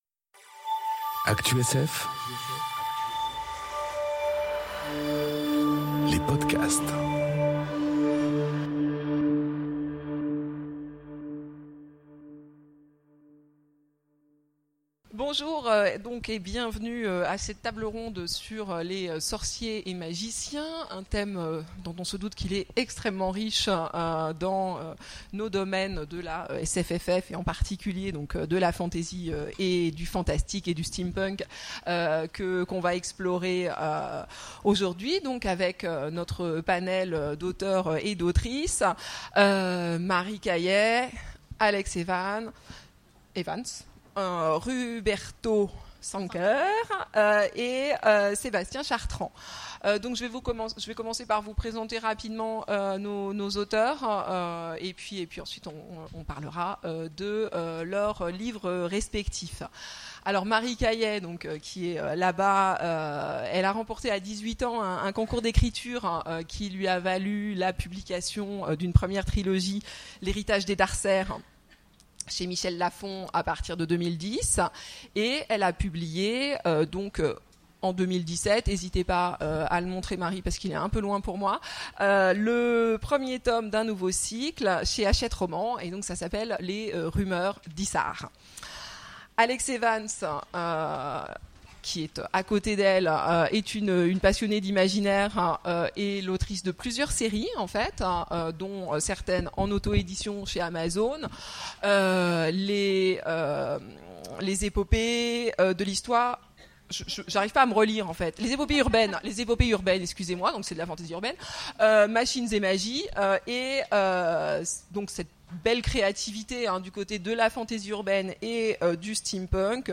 Conférence Les sorciers face à la magie : défendre le Bien ou choisir le Mal ? enregistrée aux Imaginales 2018